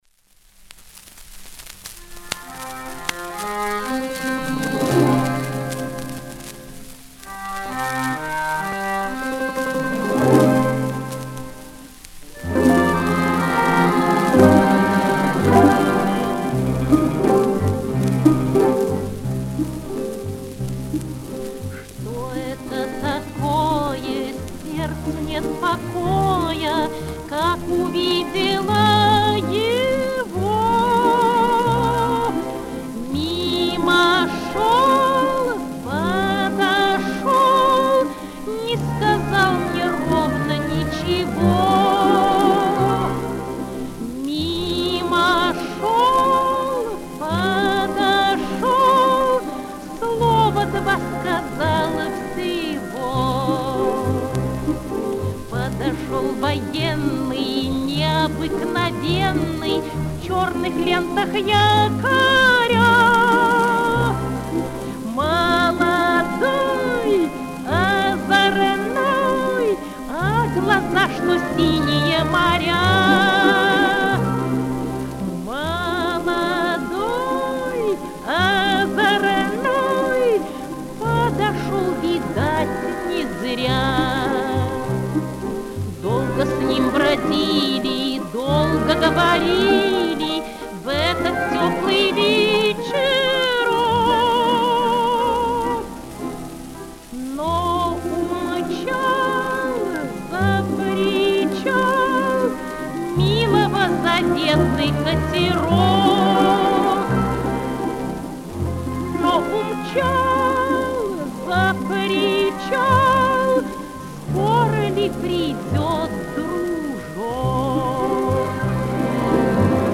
114 лет со дня рождения певицы (меццо-сопрано), фольклориста, педагога Ирмы Петровны Яунзем